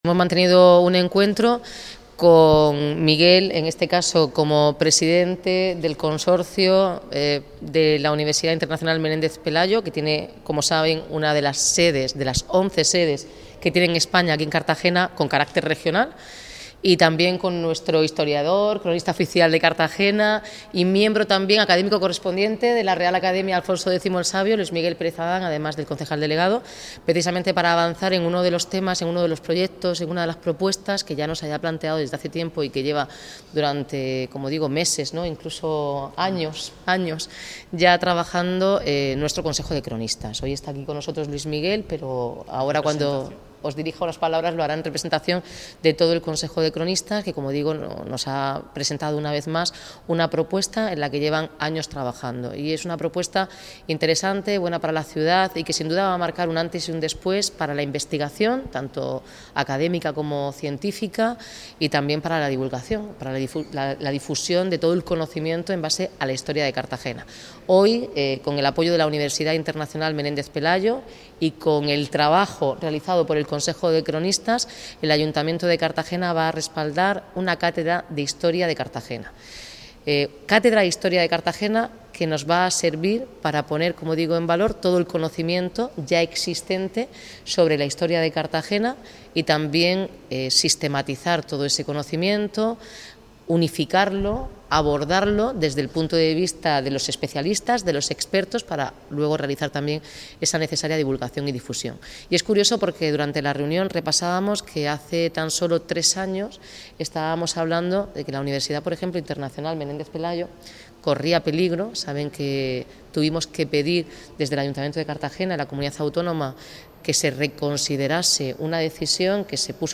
Enlace a Declaraciones de Noelia Arroyo